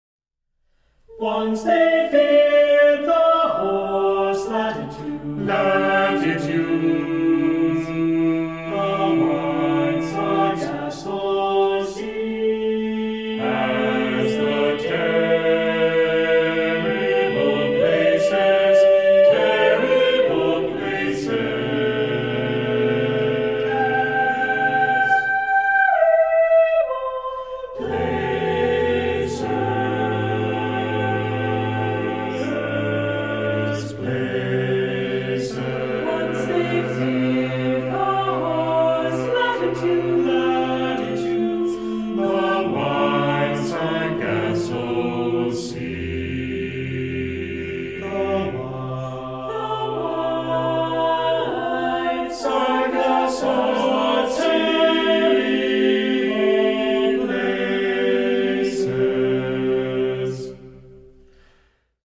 • Genres: Choral Music